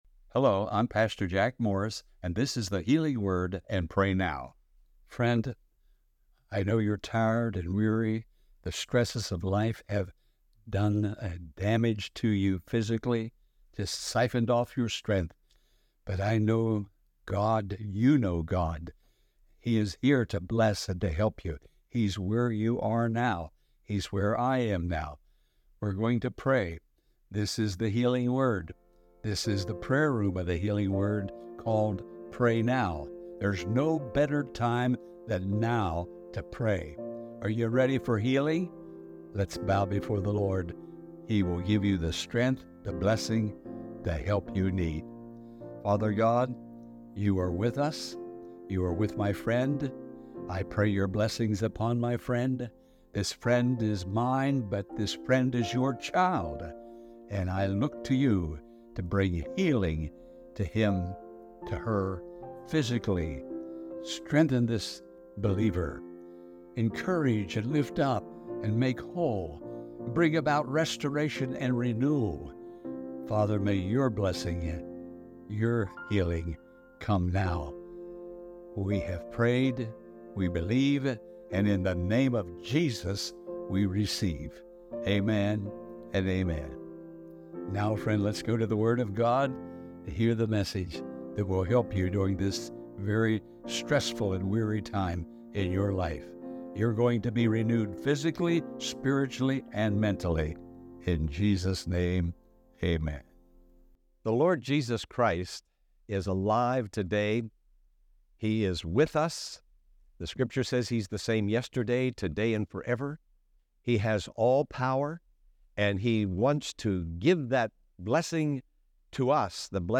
The Prayer of Healing
The-Prayer-of-Healing.mp3